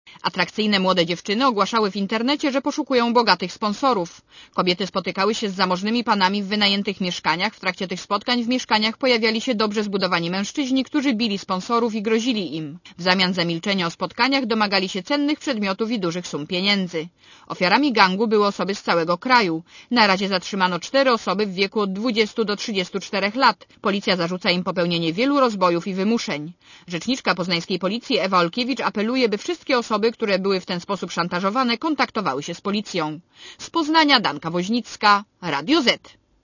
Posłuchaj relacji reporterki Radia Zet (141 KB)